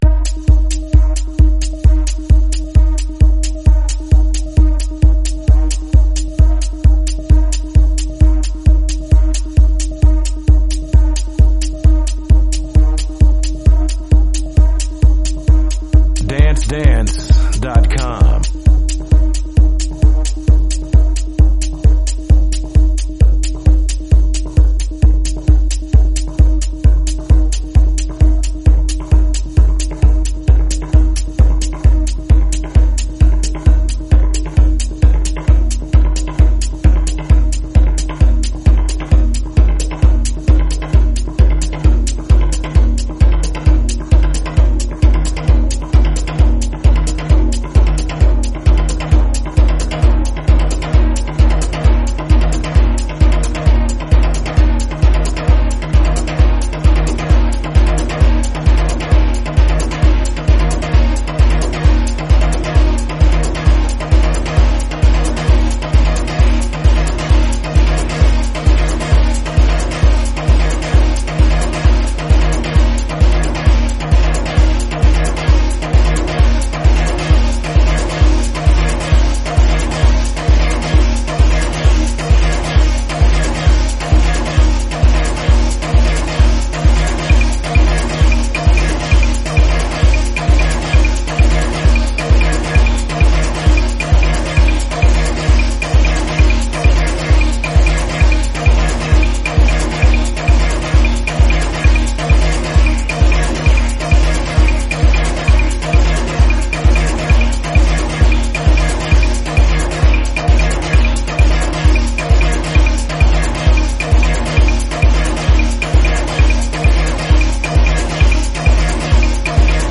Detroit Techno/Tech House track